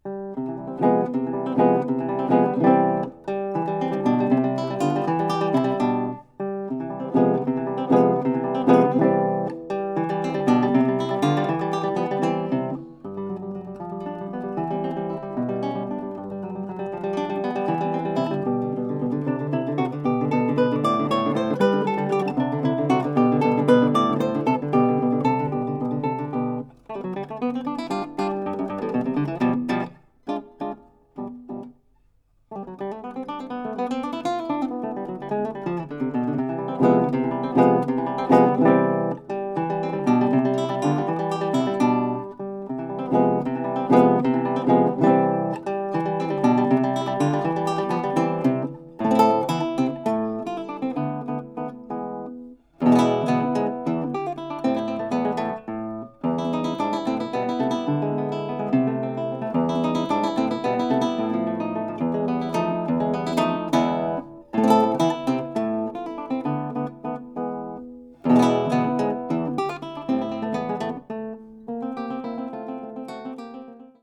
Sono qui presentate tre sonate per chitarra sola
chitarra